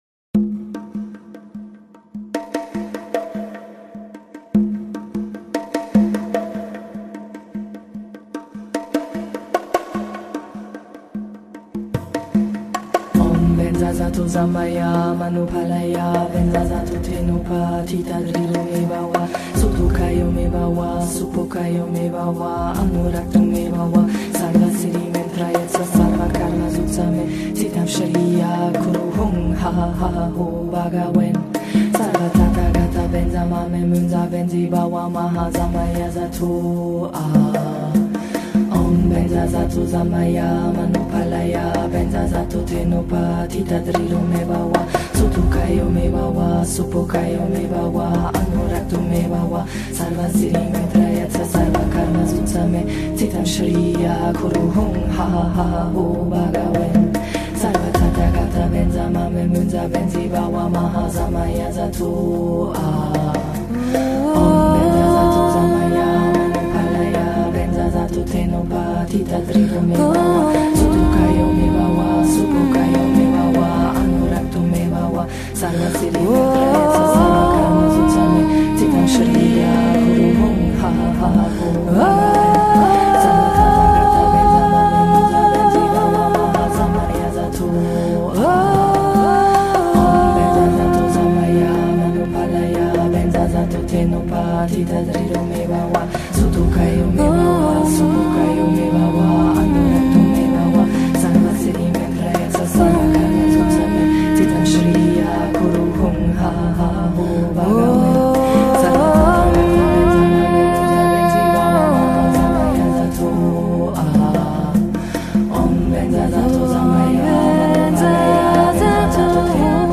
Мантра Очищения - Стослоговая Мантра